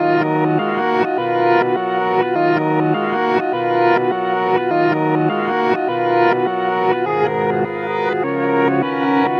快捷钢琴102
标签： 102 bpm Trap Loops Piano Loops 1.58 MB wav Key : Fm Cubase
声道立体声